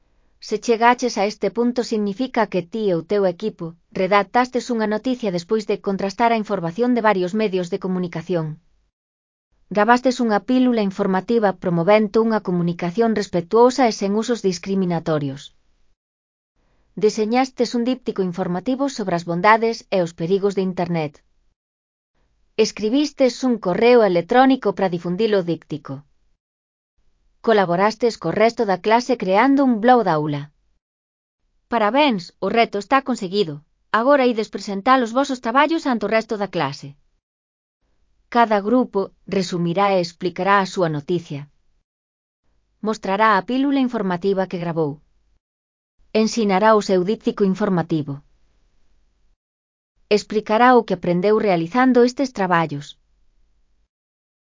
Elaboración propia (Proxecto cREAgal) con apoio de IA, voz sintética xerada co modelo Celtia. Presentamos os traballos (CC BY-NC-SA)